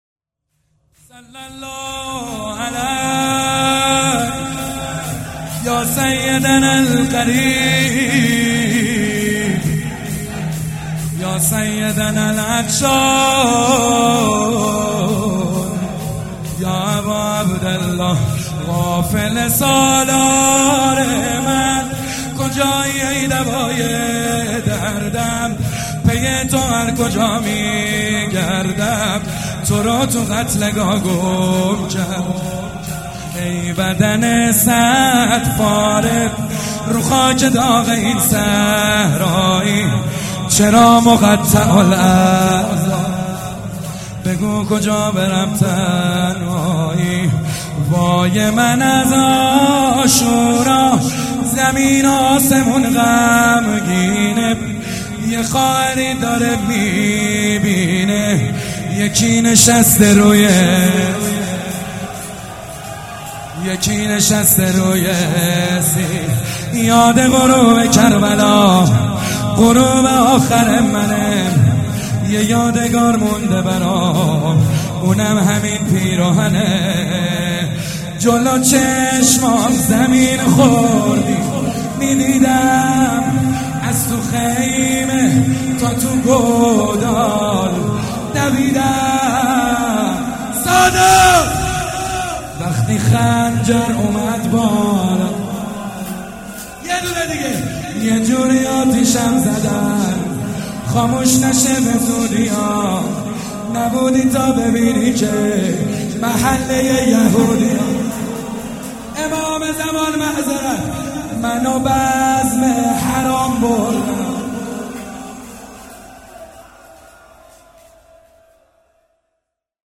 شور
مداح
وفات حضرت زینب (س)